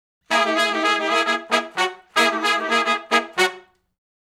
Index of /90_sSampleCDs/Sonic Foundry (Sony Creative Software) - Crimson Blue and Fabulous Horncraft 4 RnB/Horncraft for R&B/Sections/011 Funk Riff
011 Funk Riff (F#) har.wav